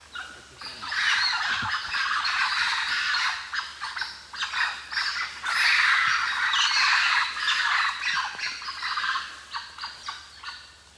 Captions English A troop of brown woolly monkey's shouting an alarm call
A troop of brown woolly monkey's shouting an alarm call in Tiputini, Ecuador
Brown_woolly_monkey_alarm_call.wav